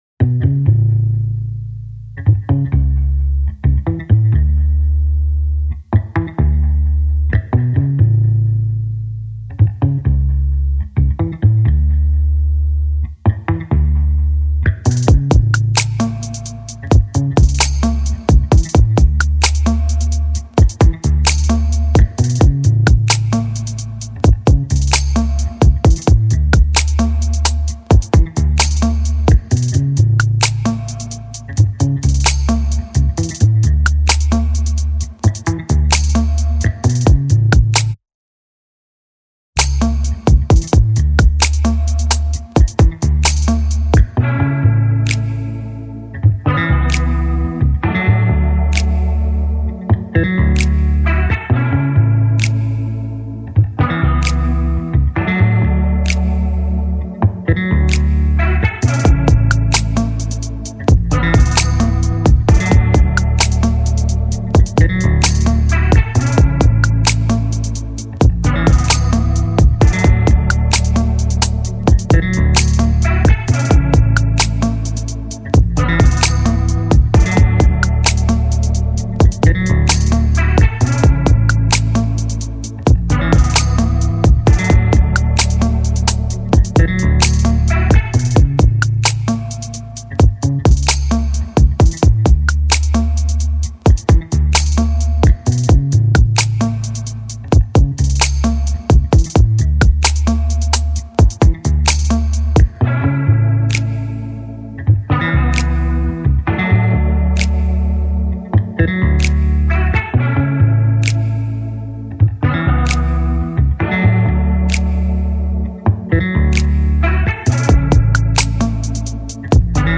R&B Instrumentals